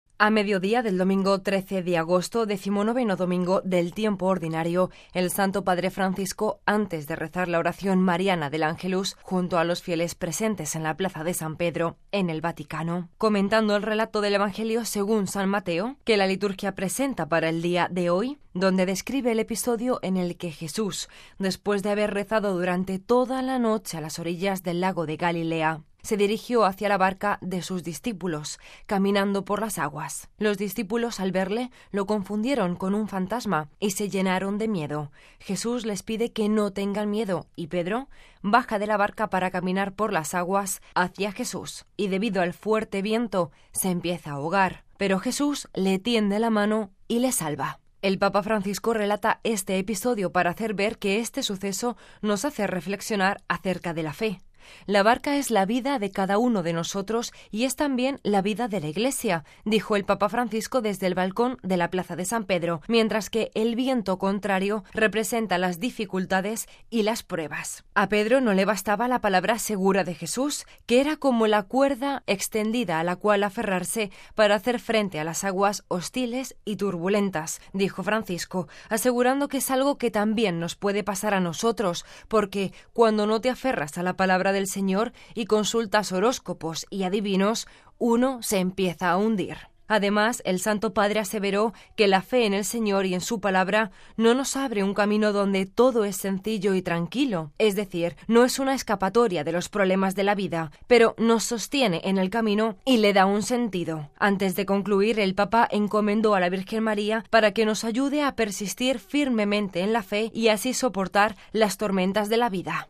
“La barca es la vida de cada uno de nosotros y es también la vida de la Iglesia”, dijo el Papa Francisco desde el balcón de la Plaza de San Pedro, mientras que “el viento contrario representa las dificultades y las pruebas”.